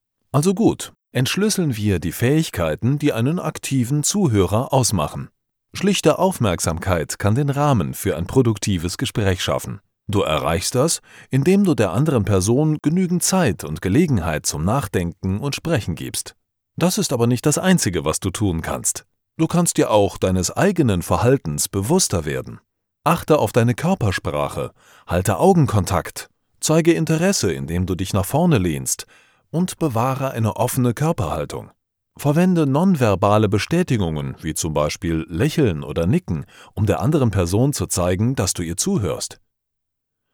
Sprechprobe: eLearning (Muttersprache):
German voice over artist.